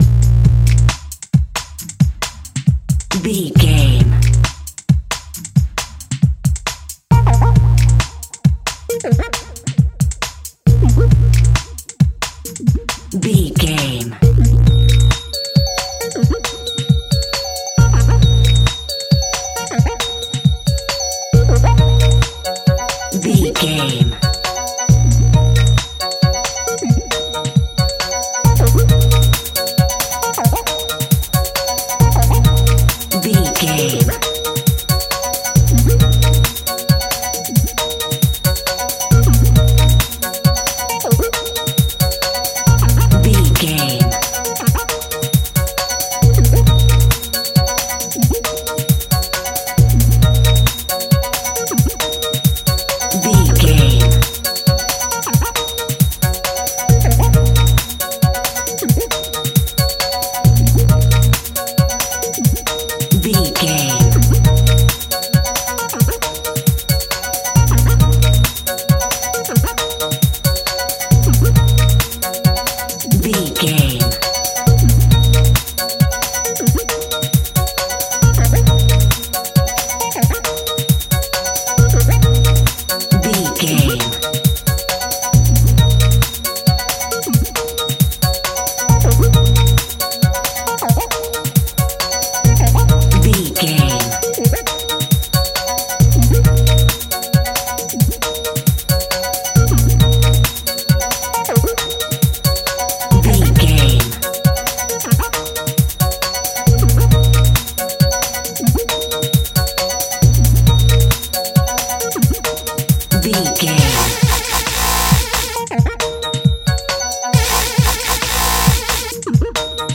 Epic / Action
Fast paced
Phrygian
B♭
aggressive
powerful
dark
driving
energetic
intense
groovy
drum machine
synthesiser
futuristic
breakbeat
synth drums
synth leads
synth bass